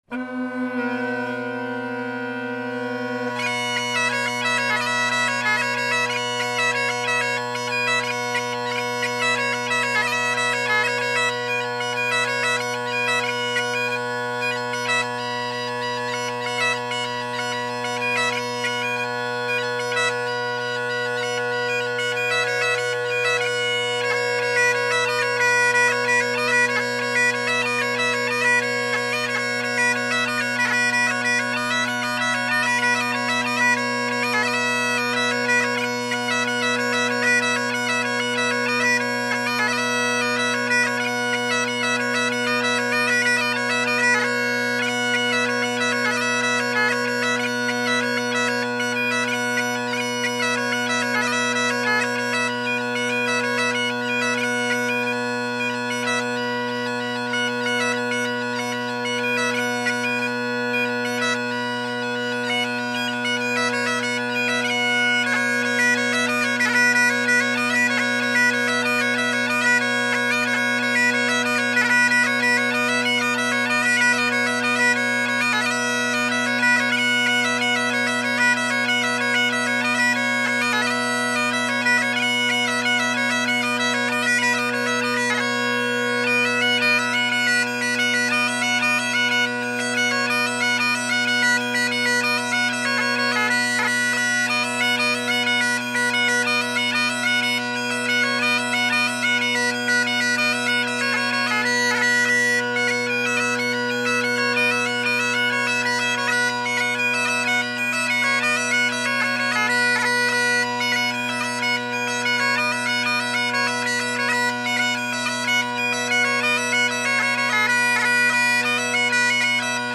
Great Highland Bagpipe Solo
Good volume on the overtones but not harsh, which is nice. There are some crazy harmonics going on on B and especially low G, listen for it!
The first set the drones start out all right but then go out a bit as I’m getting warmed up still, so I recorded it again but at the time I was trying to fix the D tuning and forgot about the drones.
The first one the drones are in tune (at least at the start); the second one the D is in tune (I think).